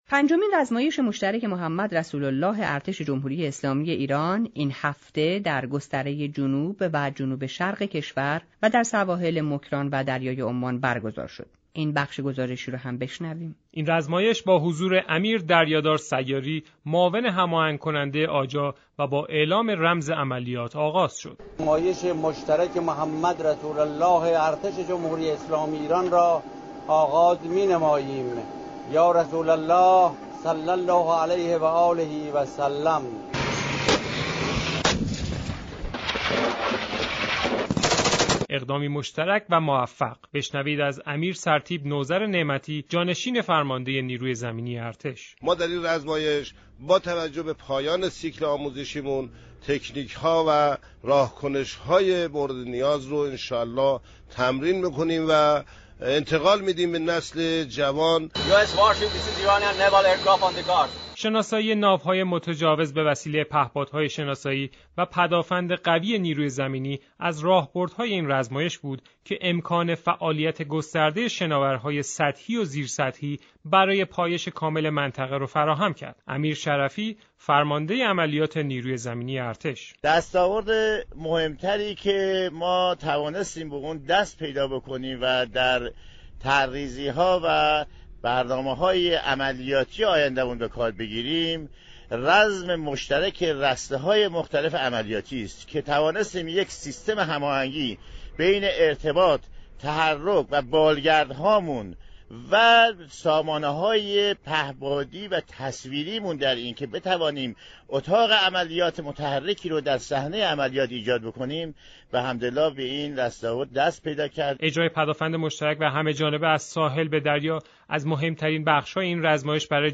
بخش گزارش هفته رویدادهای هفته رادیو ایران را بشنوید